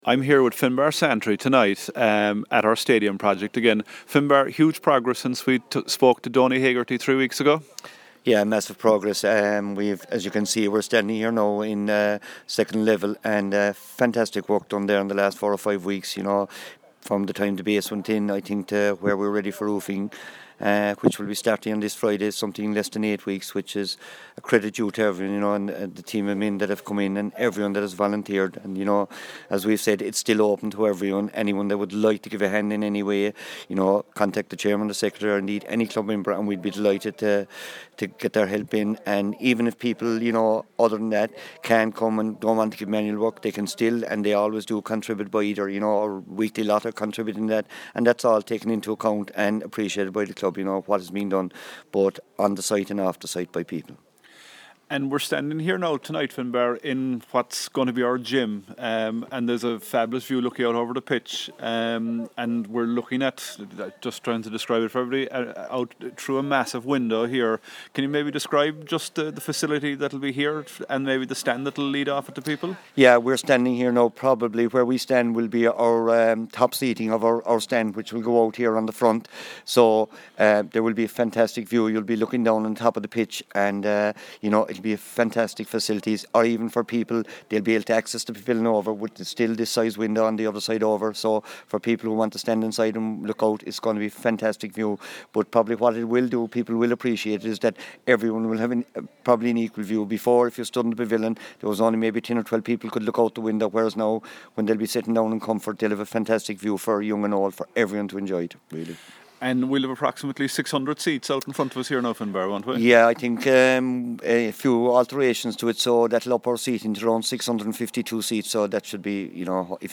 Interview
from the upper floor of our clubhouse extension